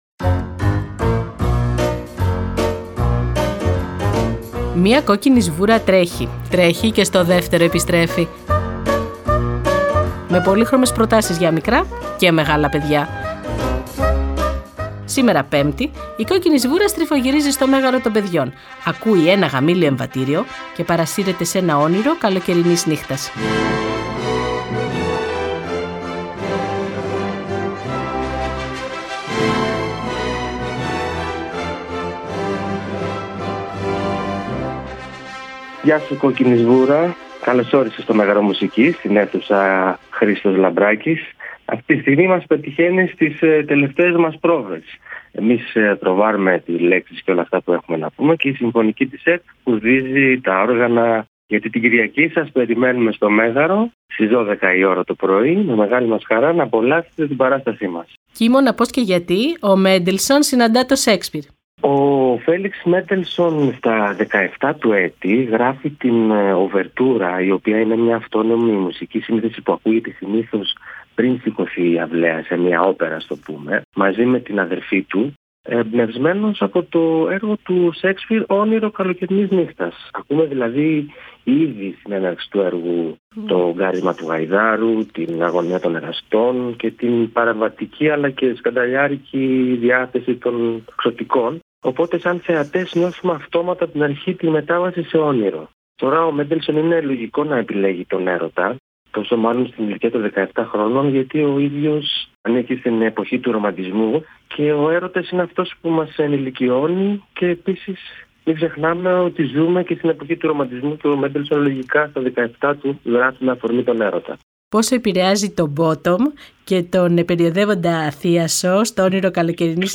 Σήμερα Πέμπτη η Κόκκινη Σβούρα στριφογυρίζει στο Μέγαρο των παιδιών, ακούει ένα γαμήλιο εμβατήριο και παρασύρεται σε ένα Όνειρο καλοκαιρινής Νύχτας.